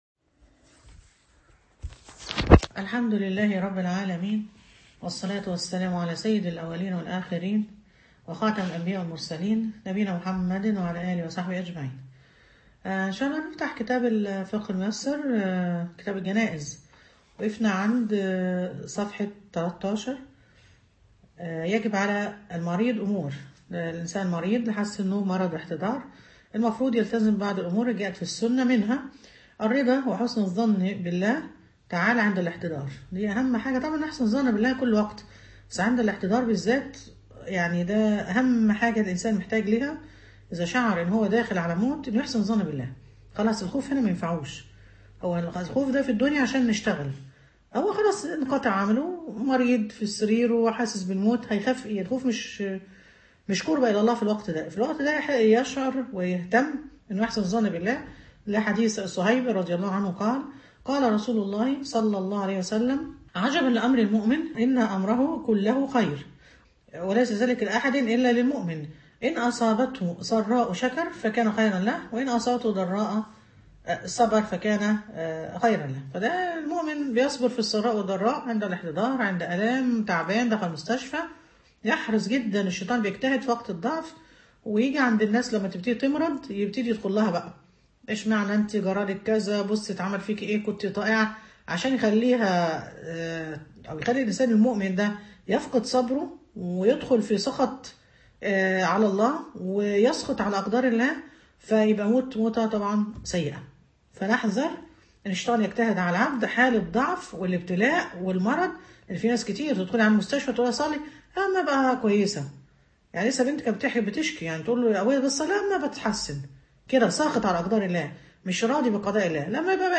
فقه الجنائز_المحاضرة الثانية